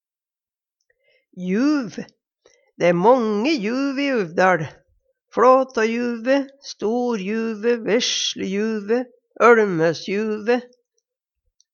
juv - Numedalsmål (en-US)